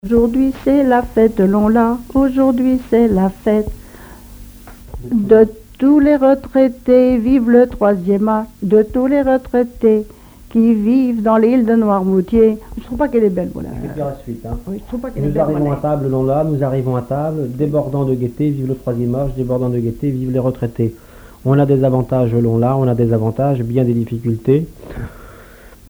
Mémoires et Patrimoines vivants - RaddO est une base de données d'archives iconographiques et sonores.
collecte en Vendée